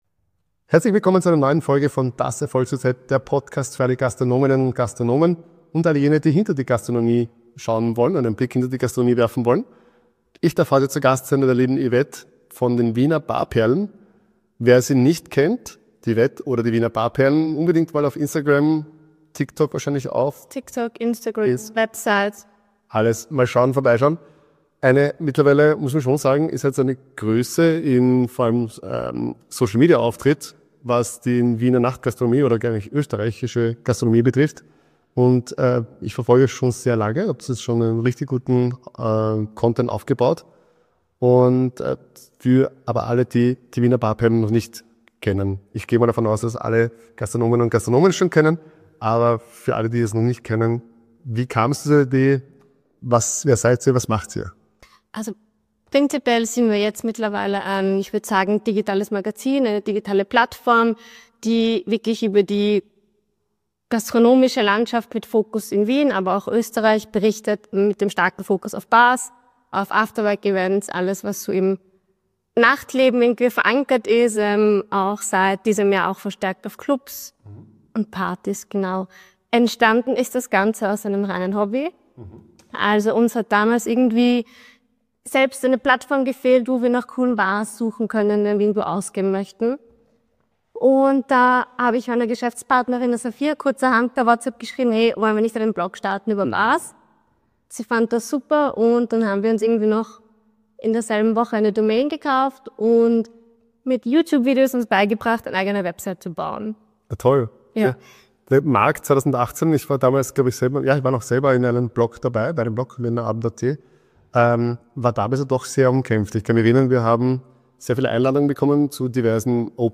Ein Gespräch über Kreativität, Community, Durchhaltevermögen und die Kraft, eigene Vorstellungen von Qualität und Atmosphäre nie aus den Augen zu verlieren.